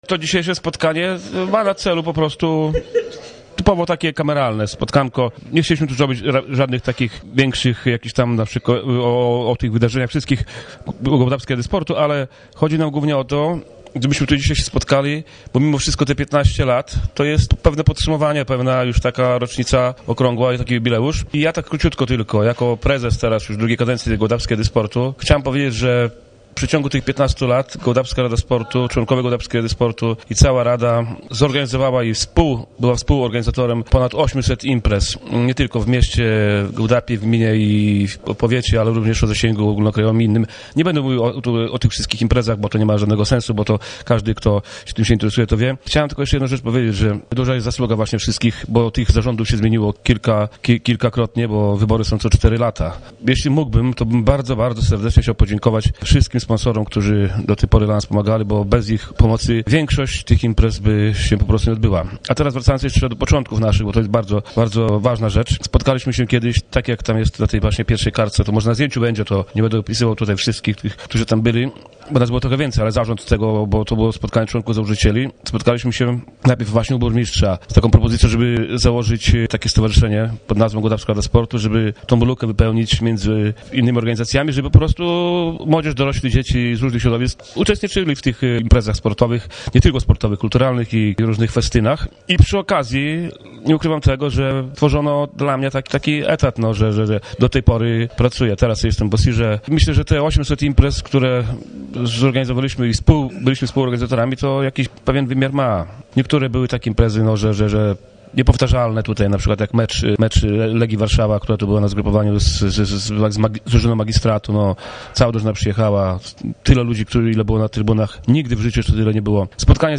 W spotkaniu w Restauracji „Matrioszka" wziął udział zarząd organizacji, a także burmistrz Marek Miros, dawniej także członek zarządu.